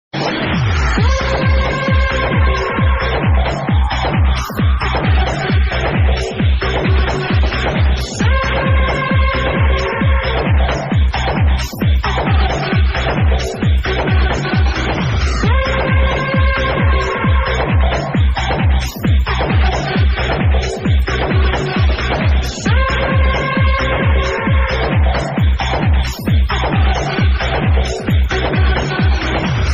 Live at Club Scanners in Dordrecht (NL)